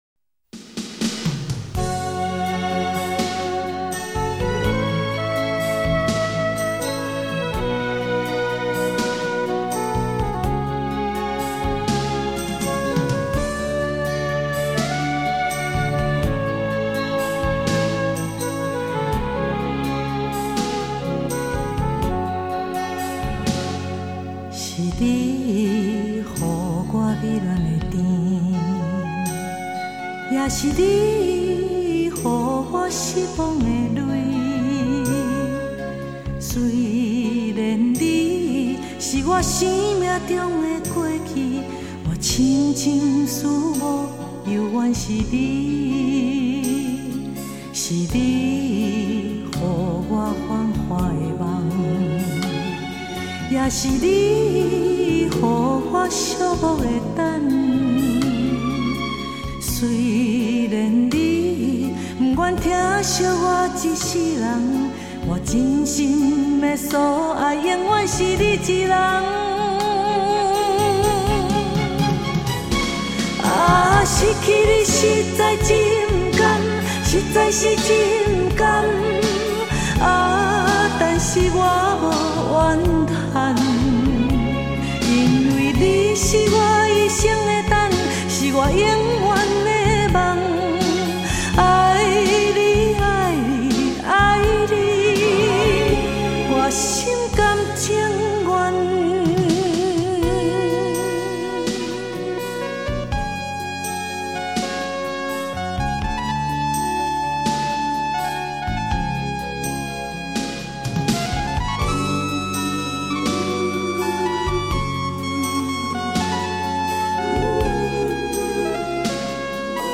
流      派: 流行